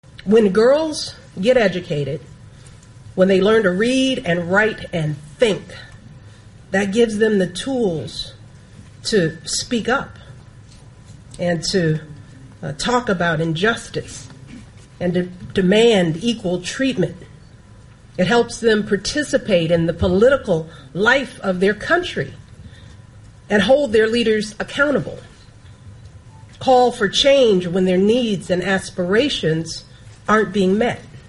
ពាក្យសម្តីផ្ទាល់របស់លោកស្រី Michelle Obamaនៅពេលថ្លែងក្នុងពិធីបណ្តុះបណ្តាលមួយរបស់កម្មវិធីអ្នកធ្វើការស្ម័គ្រចិត្តរបស់រដ្ឋាភិបាលអាមេរិក (Peace Corps) នៅខេត្តសៀមរាប៕